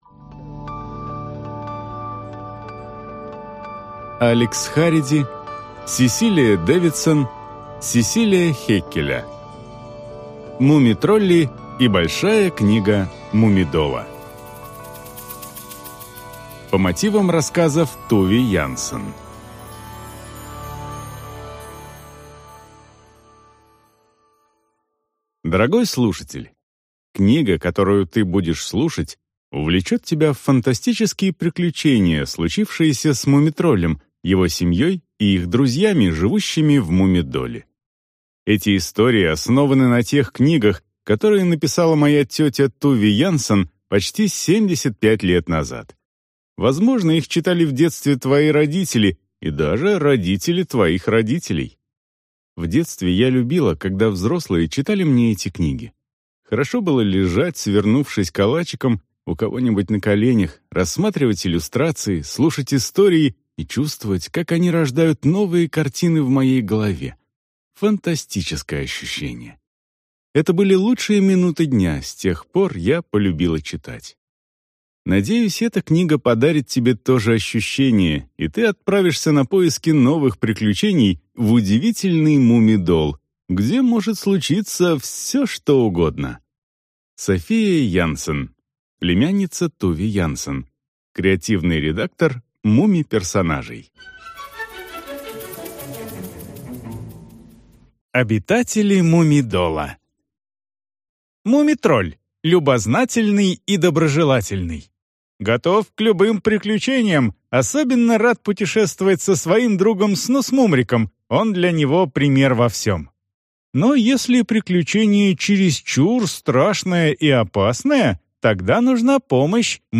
Аудиокнига Муми-тролли и Большая книга Муми-дола | Библиотека аудиокниг